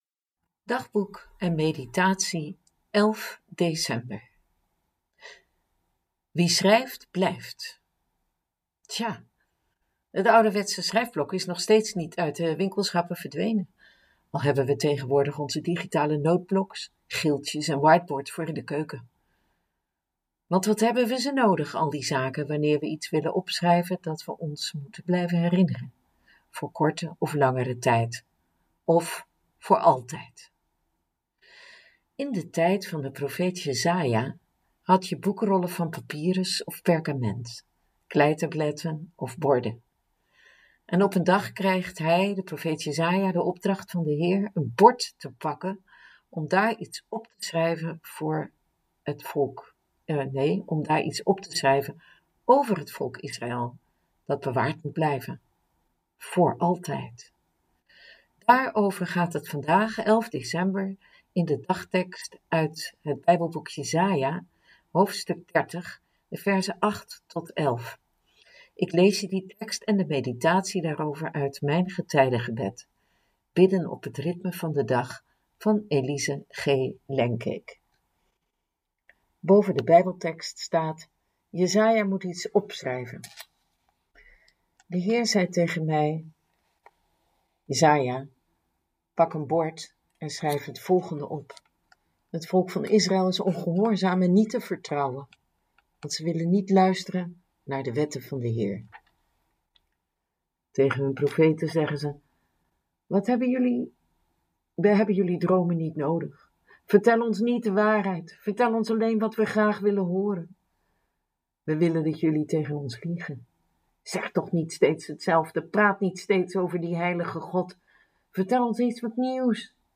Daarover gaat het vandaag, 11 december, in de dagtekst uit het bijbelboek Jesaja, hoofdstuk 30,de verzen 8-11 . Ik lees je die tekst en de meditatie daarover uit Mijn getijdengebed – Bidden op het ritme van de dag van Elise G. Lengkeek.